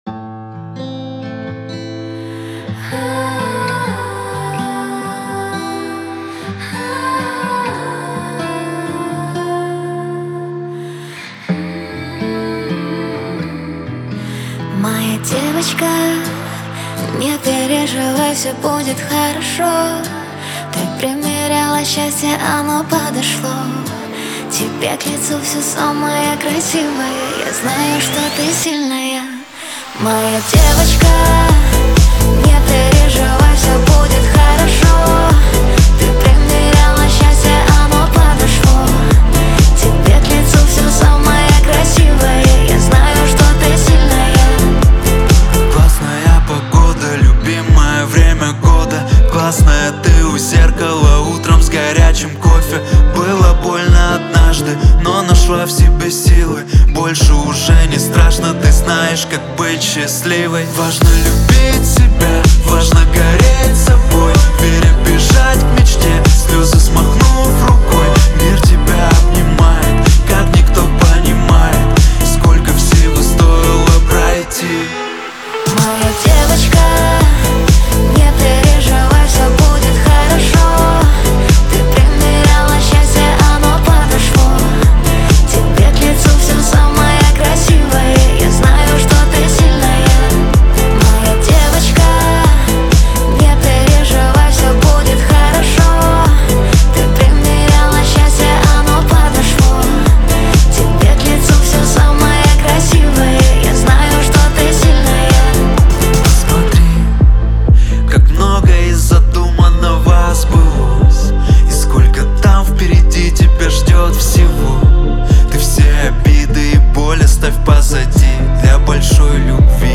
Жанр: rap | Год: 2026